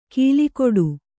♪ kīlikoḍu